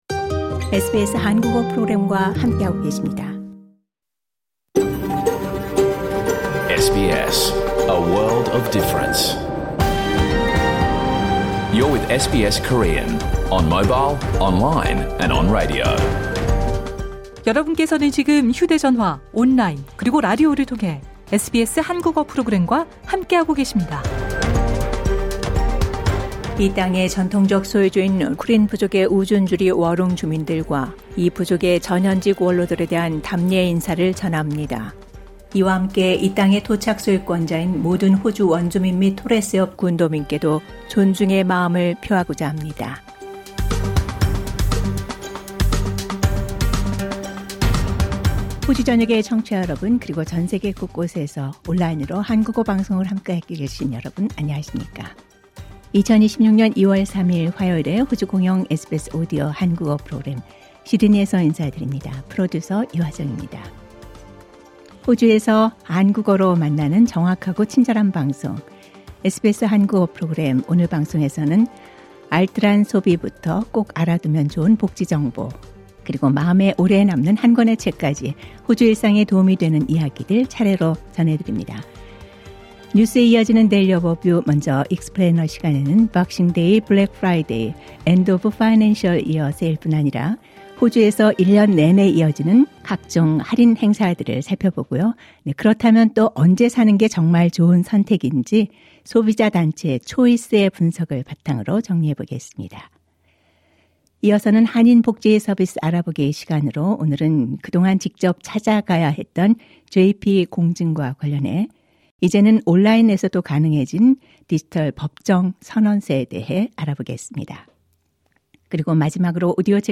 2026년 2월 3일 화요일에 방송된 SBS 한국어 프로그램 전체를 들으실 수 있습니다.